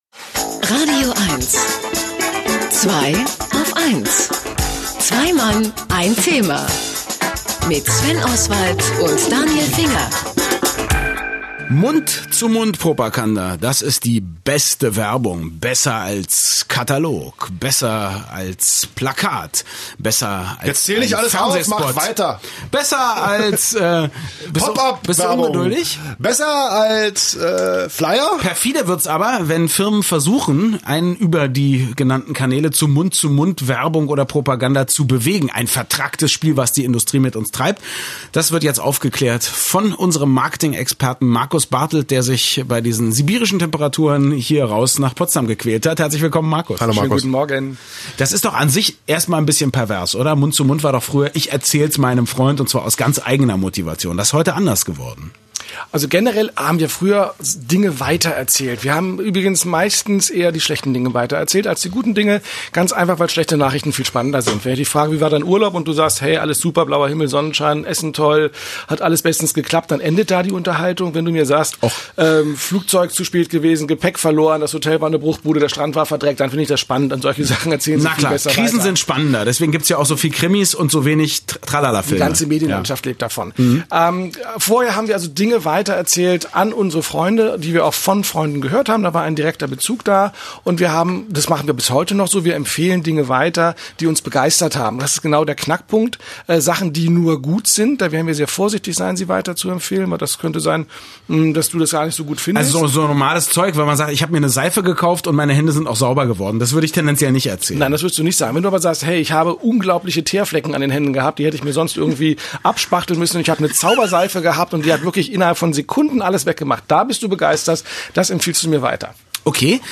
makketing - die Interviews
Die Radio-Interviews seit 2010